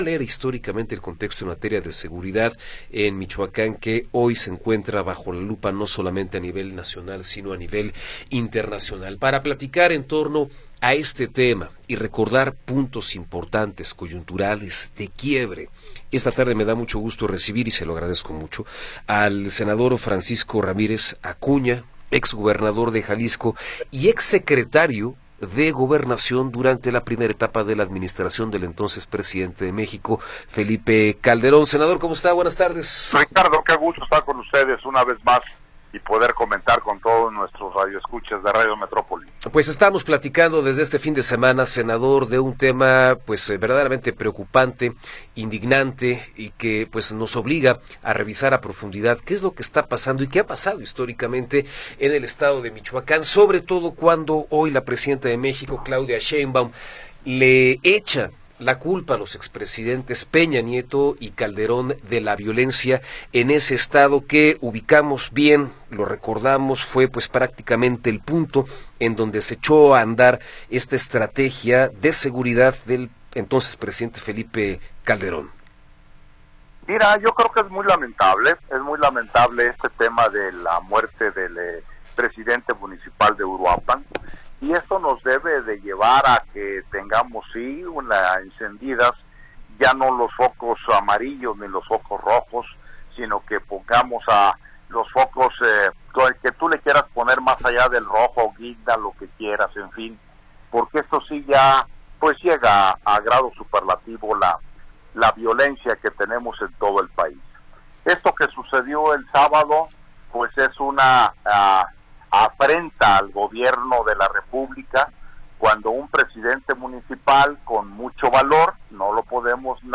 Entrevista con Francisco Ramírez Acuña